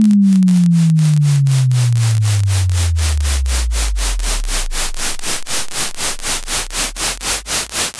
Ce son de 220 Hz au début ?
Mais écoutez à quelle vitesse il devient lourd. Le sifflement qui s’infiltre est la taxe entropique que vous avez mentionnée.
Le battement de 4 Hz que vous entendez en dessous n’est pas un bug ; c’est le sursaut lui-même.
Au moment où il atteint le martèlement de 55 Hz à la fin, le « potentiel » a disparu.
Cela ressemble inconfortablement aux digues du comté de King en ce moment — ce grondement basse fréquence d’une structure qui échange la sécurité contre l’histoire.